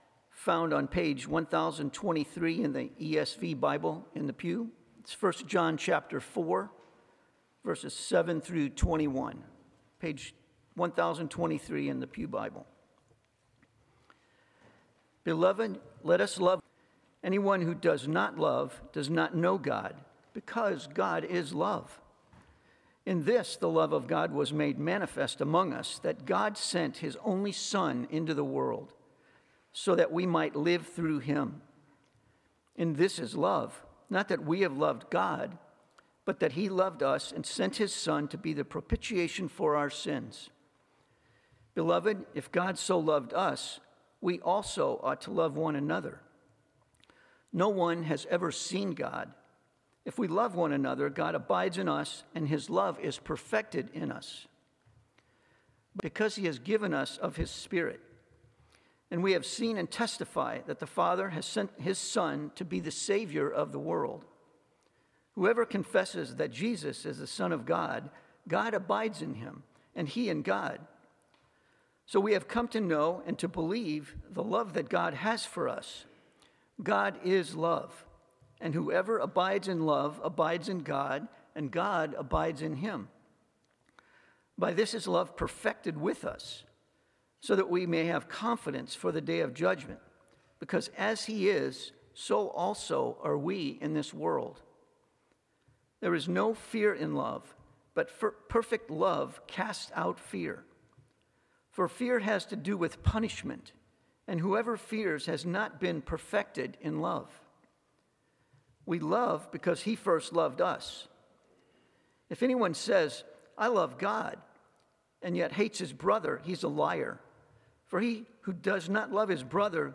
Passage: 1 John 4:7-21 Sermon